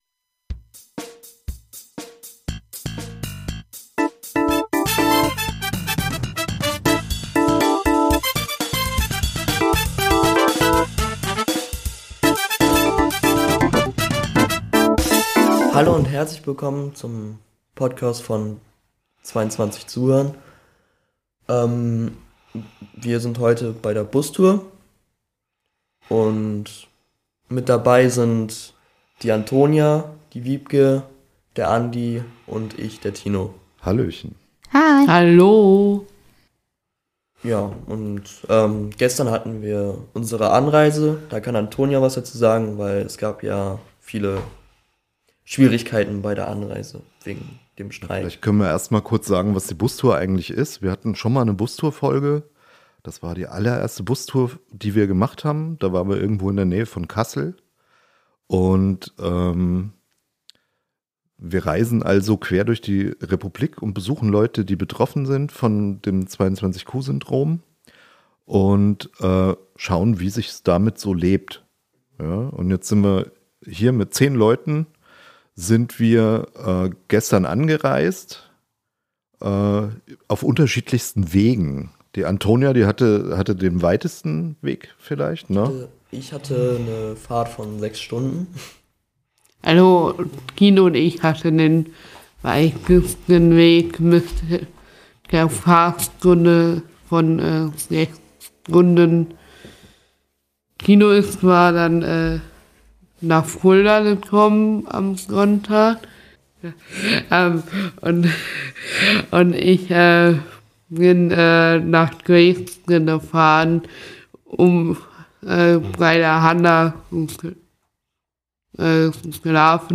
Wir sind in Zeitz in der Nudelfabrik und erzählen euch, was wir hier so vorhaben.
Laberpodcast von der Bustour